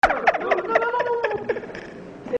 Play, download and share Risada original sound button!!!!
risada.mp3